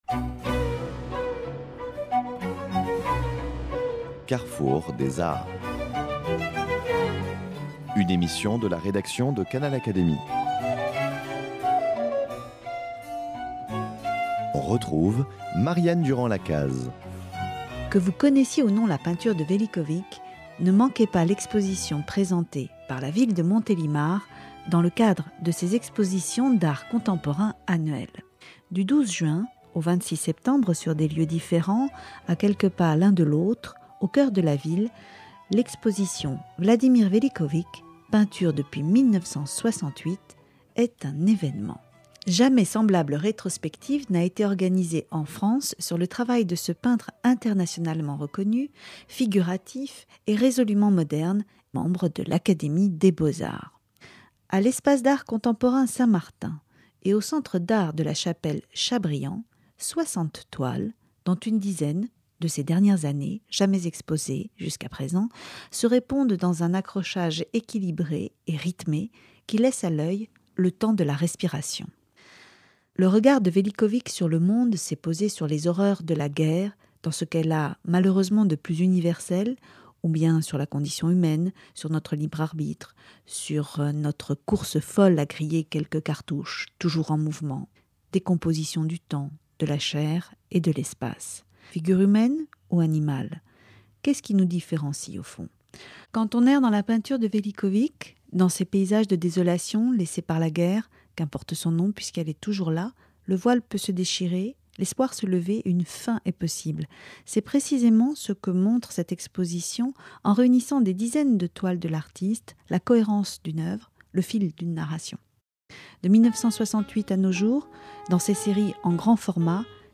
Dans cette émission, vous entendrez Vladimir Vélickovic présenter cette exposition, devant ses œuvres, à quelques heures du vernissage
courte interview